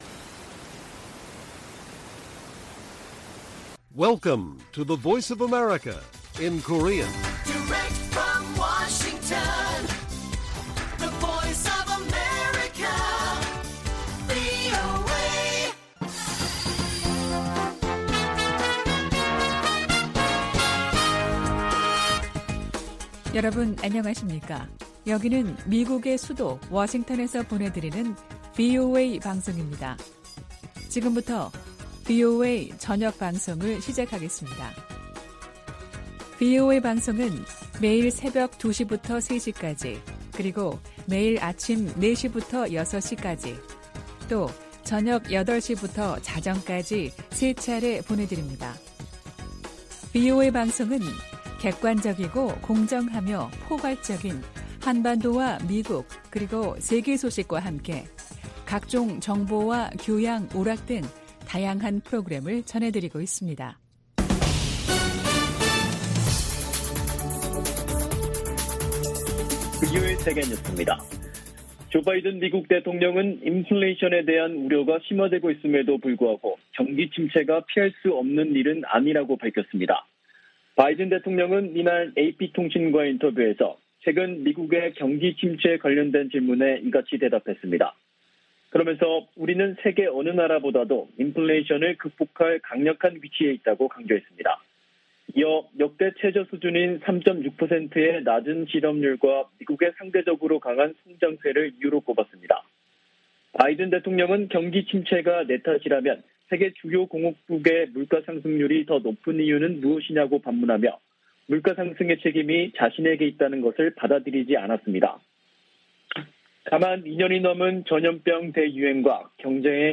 VOA 한국어 간판 뉴스 프로그램 '뉴스 투데이', 2022년 6월 17일 1부 방송입니다. 미 국무부가 대화와 외교로 북한 핵 문제를 해결한다는 바이든 정부 원칙을 거듭 밝혔습니다. 북한의 7차 핵실험 가능성이 계속 제기되는 가운데 미국과 중국이 이 문제를 논의하고 있다고 백악관 고위 당국자가 밝혔습니다. 미국의 전문가들은 북한의 풍계리 핵실험장 4번 갱도 움직임은 폭발력이 다른 핵실험을 위한 것일 수도 있다고 분석했습니다.